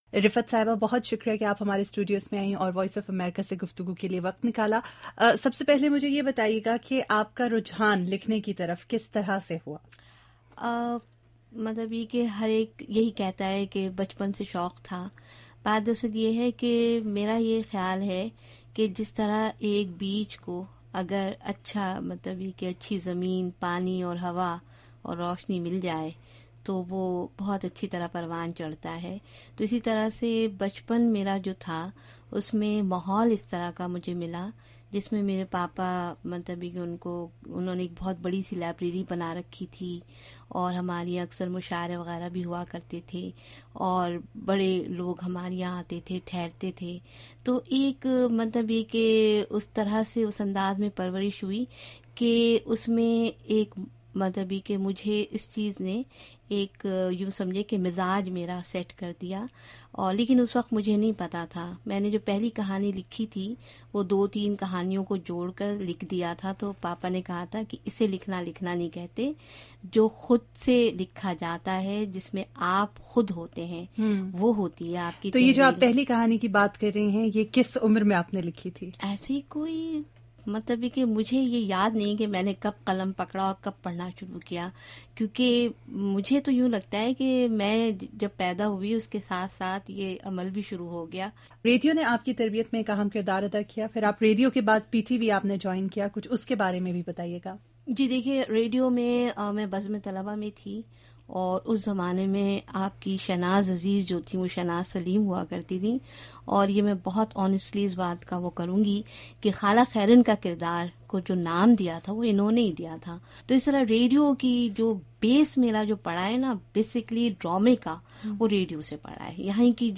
ایک ملاقات